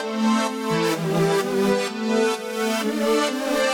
Index of /musicradar/french-house-chillout-samples/128bpm/Instruments
FHC_Pad C_128-A.wav